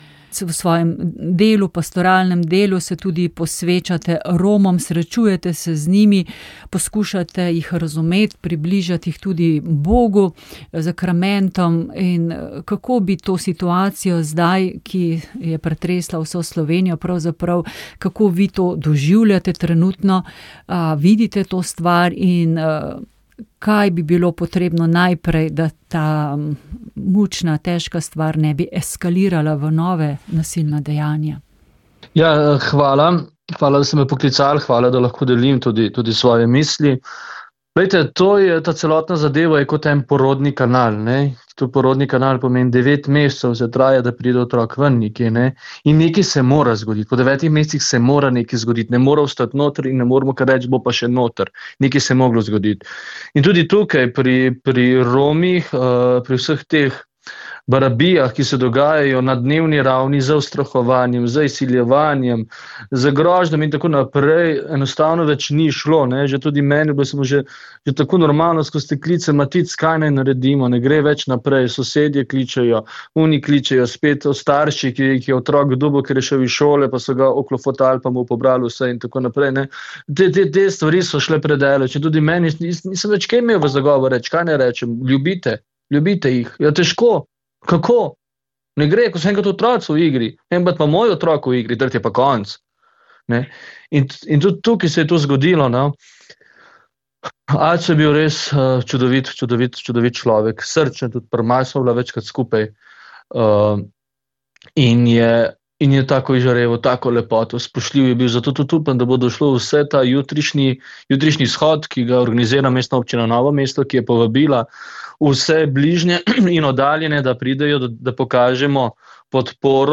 Govor
Po maši je sledil kulturni program Nove slovenske zaveze